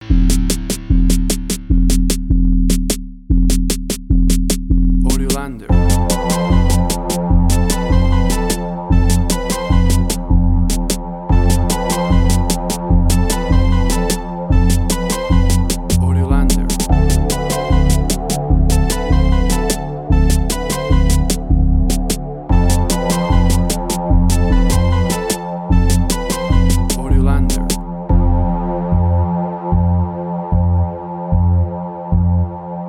Tempo (BPM): 75